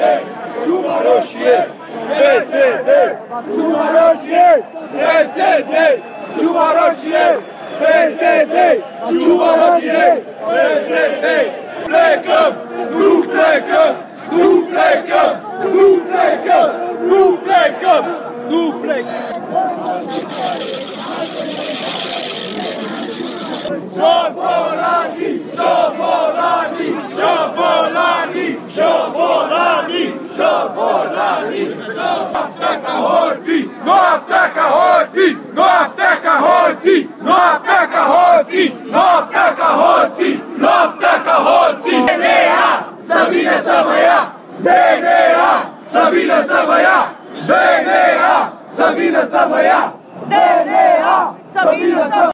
Protestatarii scandează la București în Piața Victoriei.